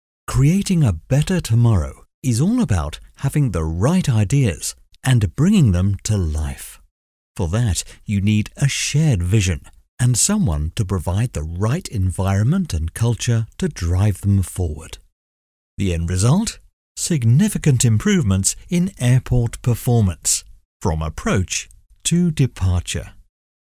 Older Sound (50+)